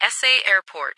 ATIS Sound Update
- New ATIS Sound files created with Google TTS en-US-Studio-O